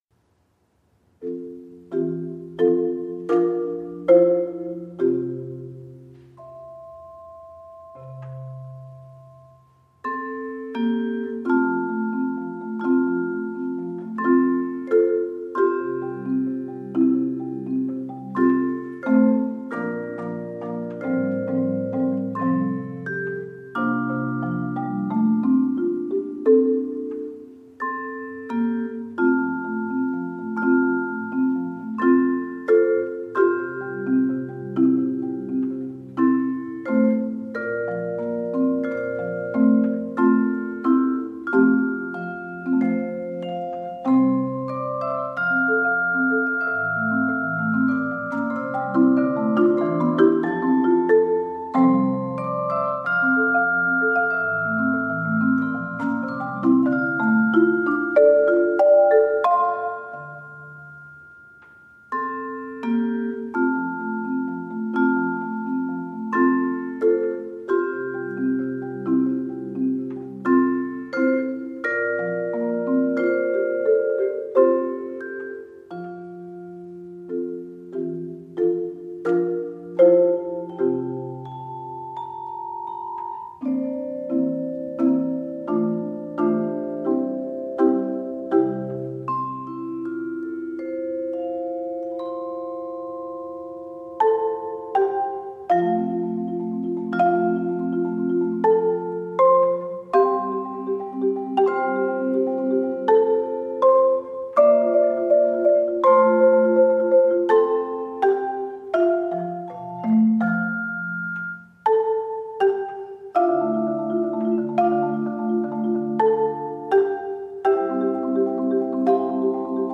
Sanctuary-May-30th-audio.mp3